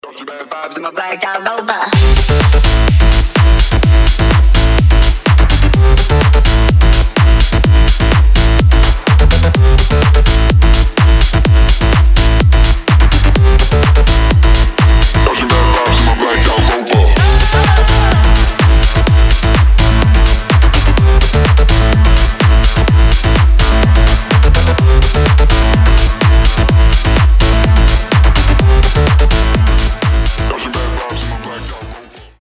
громкие
edm , future house